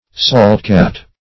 Search Result for " saltcat" : The Collaborative International Dictionary of English v.0.48: Saltcat \Salt"cat`\, n. A mixture of salt, coarse meal, lime, etc., attractive to pigeons.